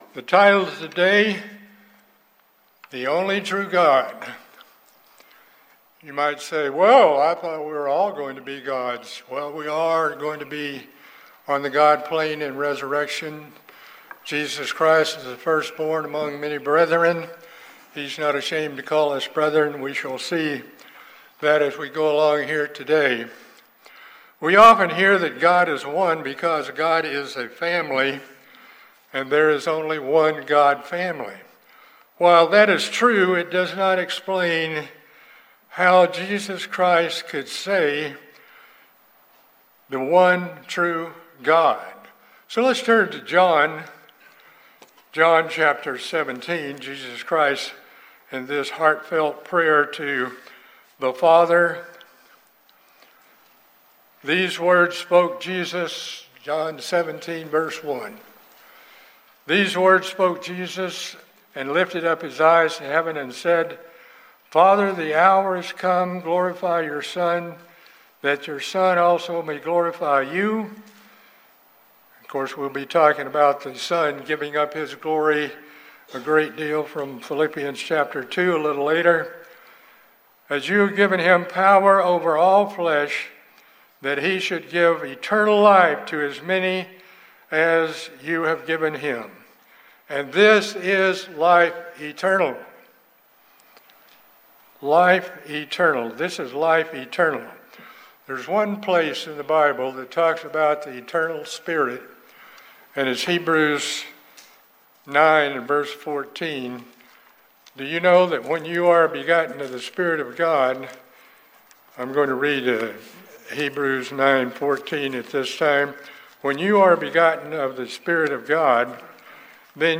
This sermon explores why Jesus Christ could say in John 17:3, that the Father is the only true God.